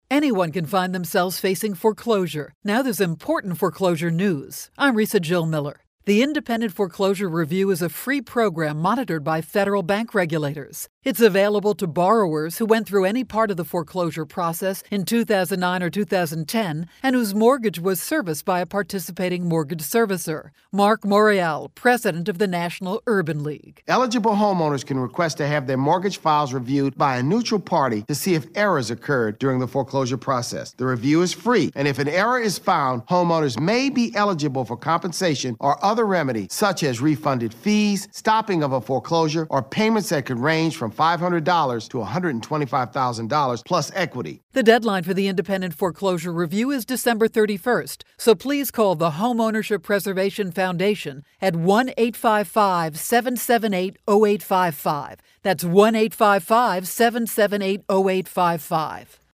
November 15, 2012Posted in: Audio News Release